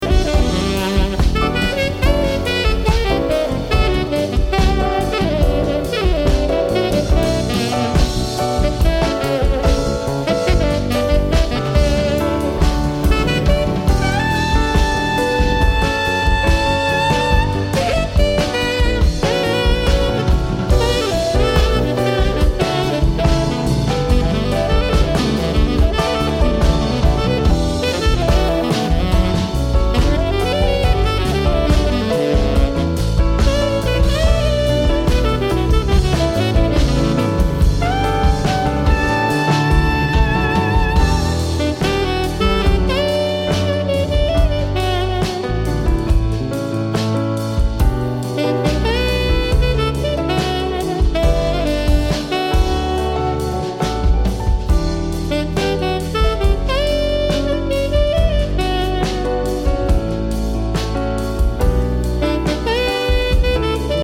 sassofoni tenore e soprano
piano e tastiere
basso elettrico
batteria